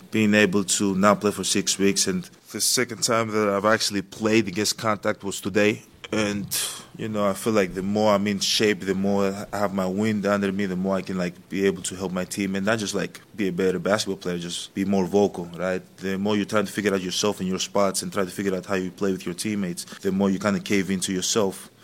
Antetokounmpo said that he’s just trying to get back into rhythm.